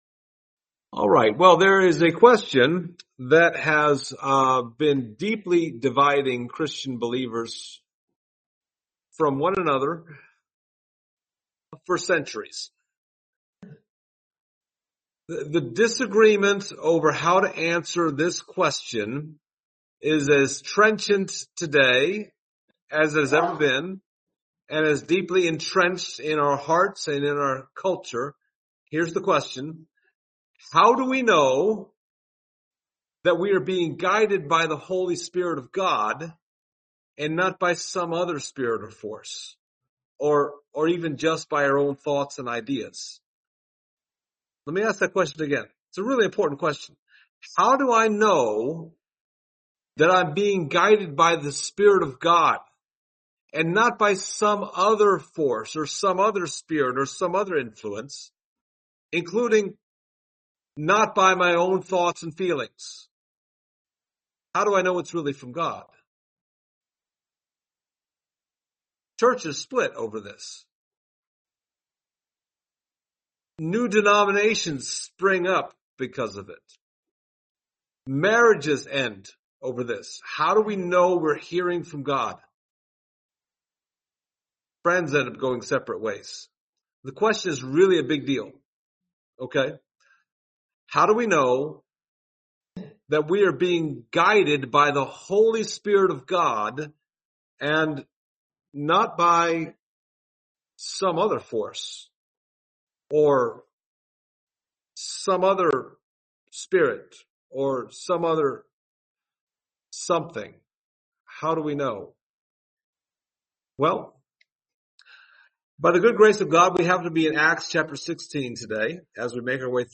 The Acts of the Holy Spirit Passage: Acts 16:6-10 Service Type: Sunday Morning Topics